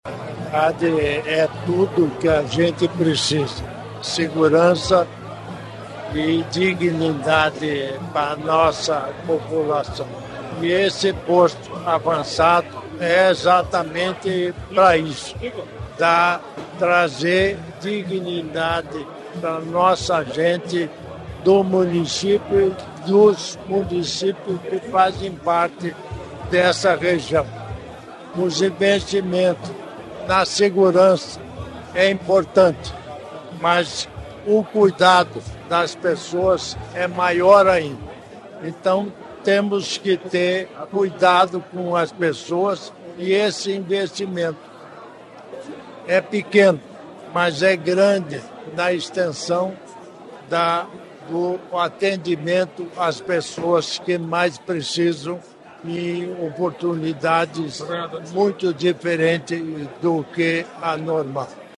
Sonora do governador em exercício Darci Piana sobre o novo Posto Avançado da Polícia Científica em Irati